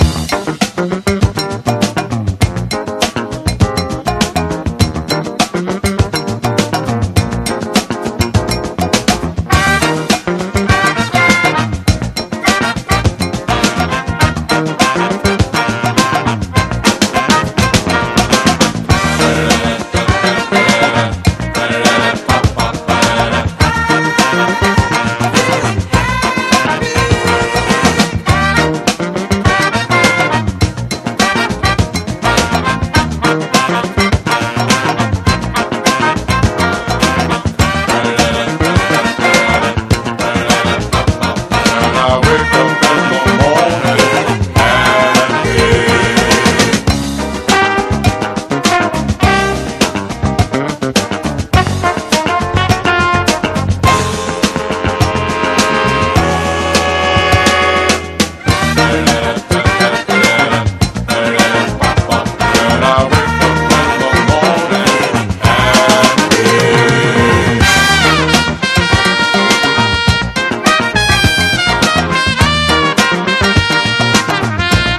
ROCK / 90''S～ / NEO-ACO/GUITAR POP (UK)
甘酸っぱくも疾走感のあるナンバーが満載。